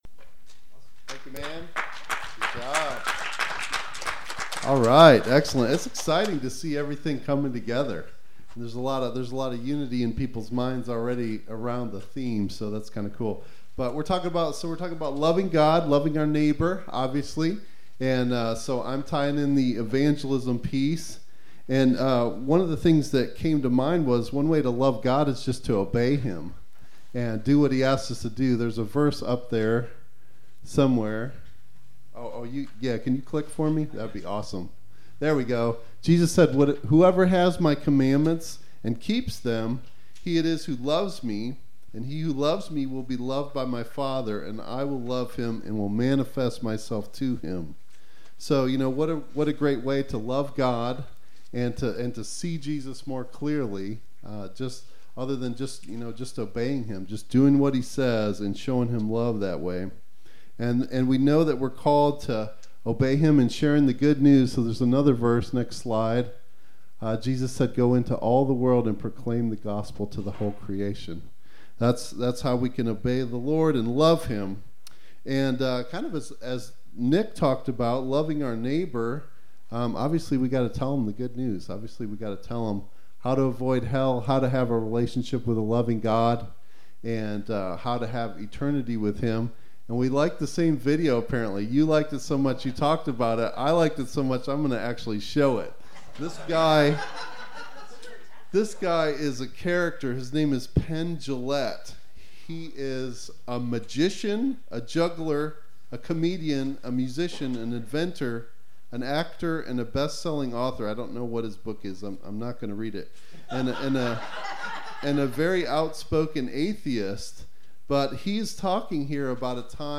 Our audio sermon podcast is available on most podcasting services including Spotify, Apple Podcasts, Stitcher, Google Podcasts and more!